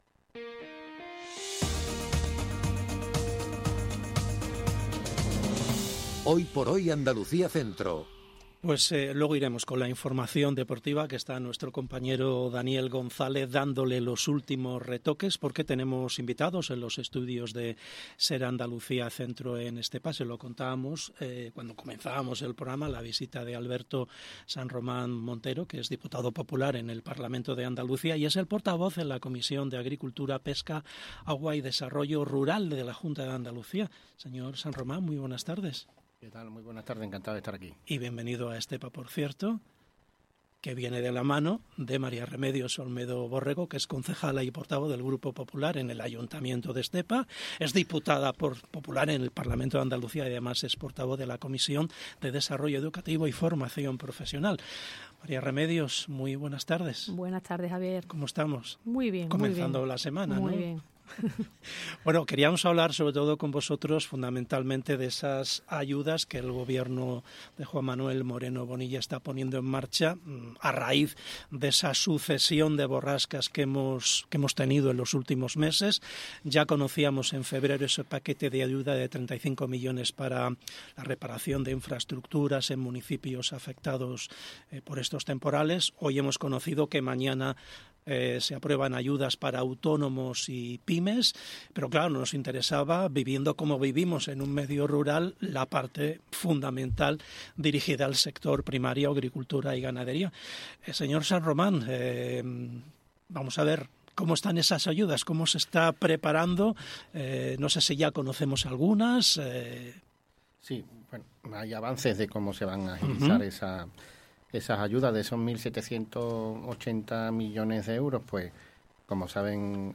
ENTREVISTA ALBERTO SANROMAN EN HOYxHOY - Andalucía Centro
ENTREVISTA ALBERTO SANROMAN EN HOYxHOY El Gobierno de la Junta de Andalucía destinará un montante de 1.780 millones de euros en ayudas dirigidas a agricultores y ganaderos afectados por el tren de borrascas que han afectado al sector primario andaluz; ayudas que empezar a percibir a partir del mes de abril. Lo ha explicado, en SER Andalucía Centro, Alberto Sanromán Montero, diputado popular y portavoz en la Comisión de Agricultura, Pesca, Agua y Desarrollo Rural en el Parlamento de Andalucía.